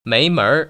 [méi//ménr] 메이머얼